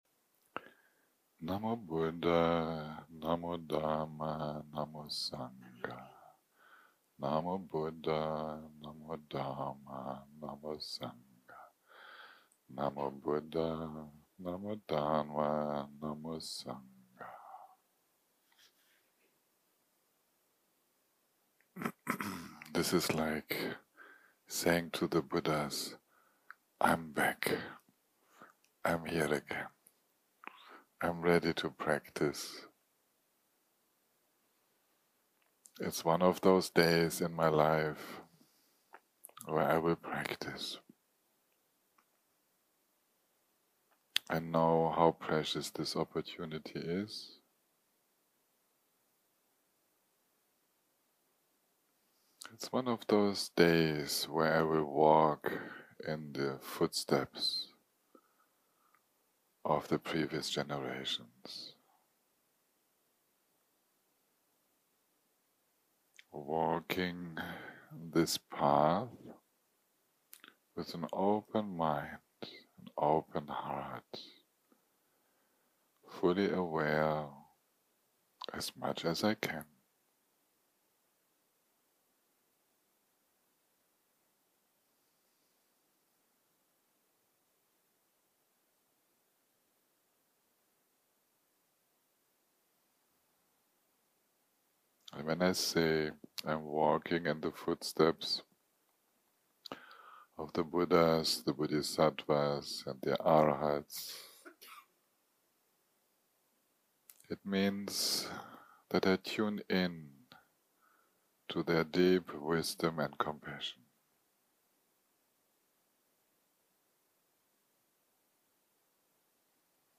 יום 6 - הקלטה 24 - בוקר - מדיטציה מונחית - All appearances are mind - part 1 0:00 0:00 סוג ההקלטה: סוג ההקלטה: מדיטציה מונחית שפת ההקלטה: שפת ההקלטה: אנגלית